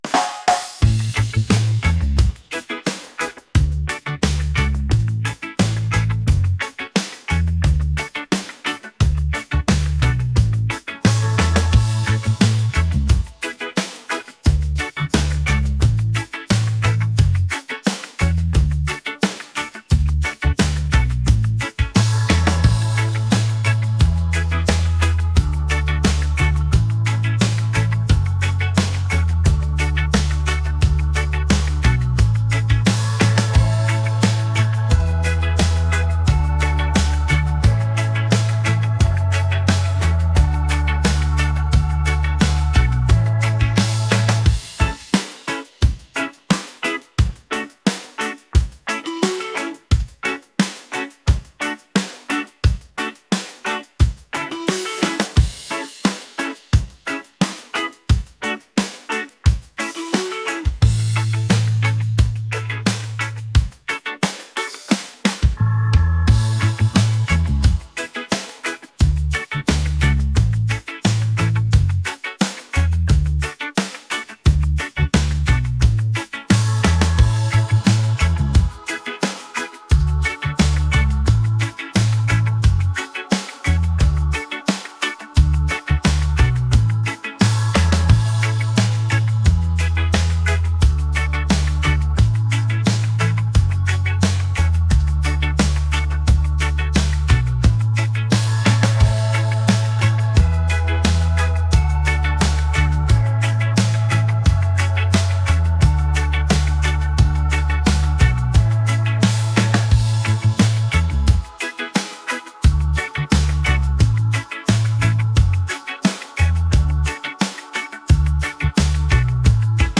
upbeat | catchy | reggae